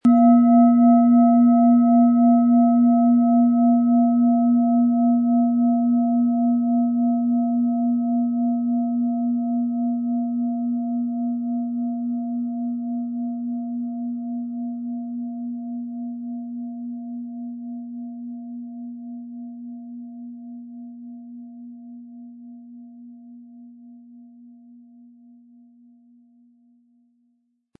Planetenton Planetenton
Chiron
• Tiefster Ton: Mond
Um den Original-Klang genau dieser Schale zu hören, lassen Sie bitte den hinterlegten Sound abspielen.
Ein schöner Klöppel liegt gratis bei, er lässt die Klangschale harmonisch und angenehm ertönen.
MaterialBronze